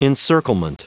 Prononciation du mot encirclement en anglais (fichier audio)